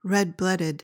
PRONUNCIATION:
(RED-BLUHD-id)